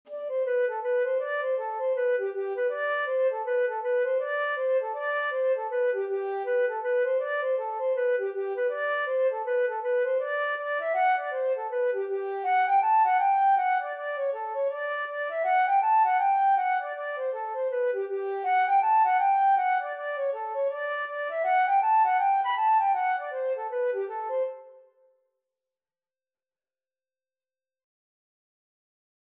G major (Sounding Pitch) (View more G major Music for Flute )
2/2 (View more 2/2 Music)
G5-B6
Flute  (View more Easy Flute Music)
Traditional (View more Traditional Flute Music)